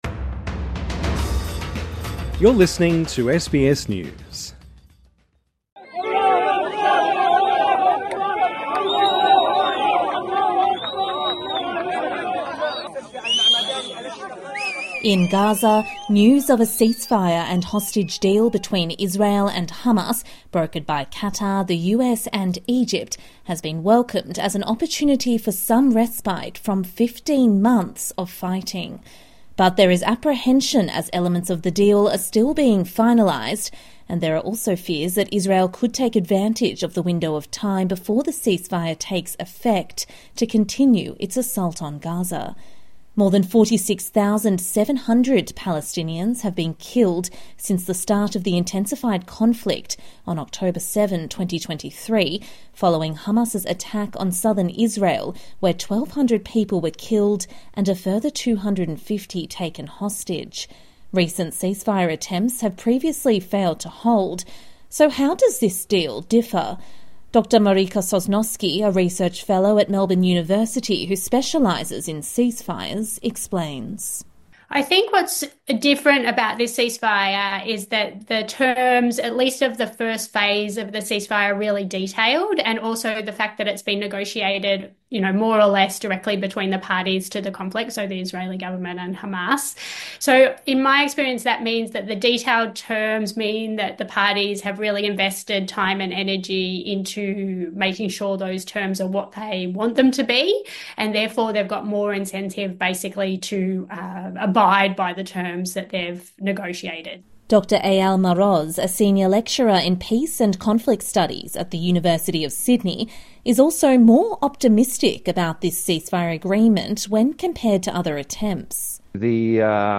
(Sounds of cheers)